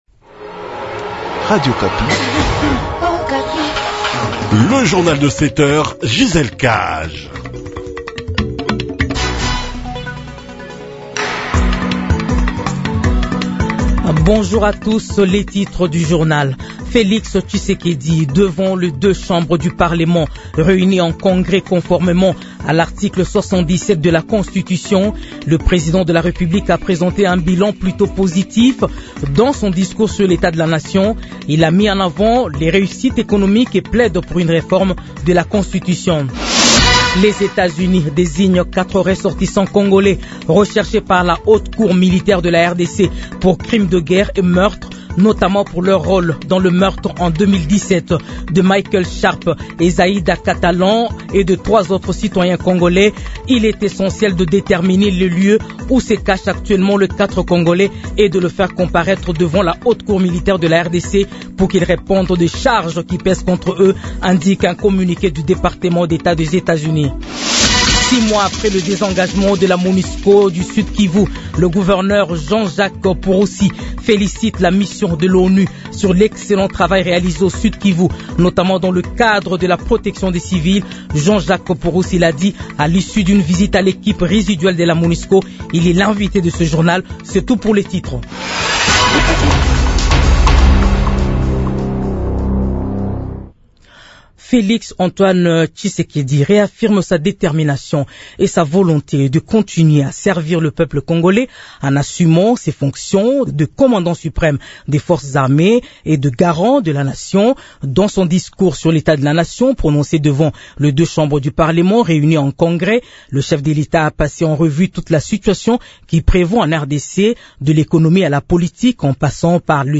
Journal de 7 heures